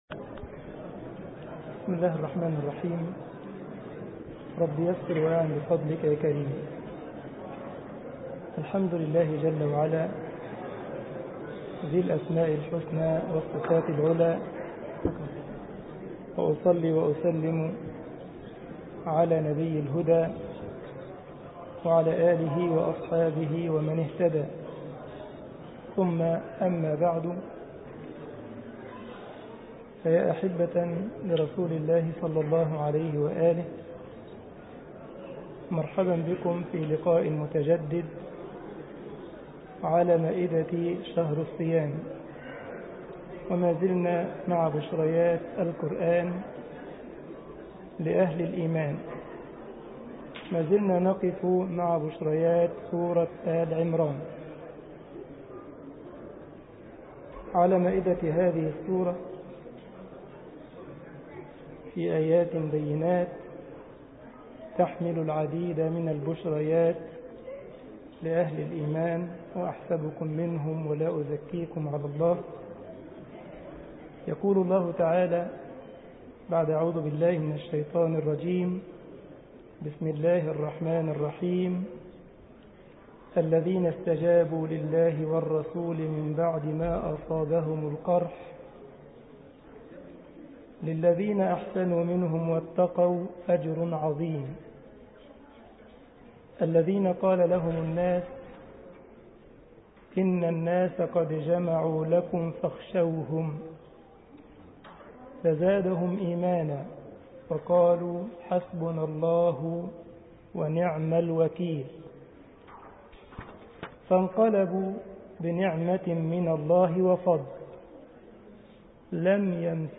مسجد الجمعية الإسلامية بالسارلند ـ ألمانيا درس 19 رمضان 1433 هـ